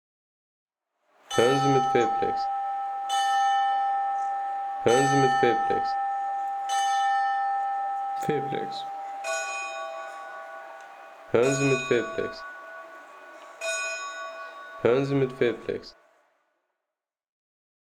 Neuschwanstein Geläute